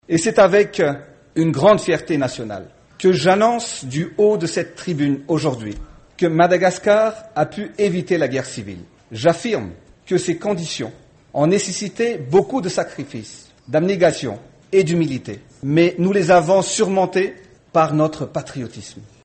La deuxième journée de la 68e session de l'Assemblée générale aura permis d'exposer les diverses crises politico-militaires du continent.
Ecoutez le président Andry Rajoelina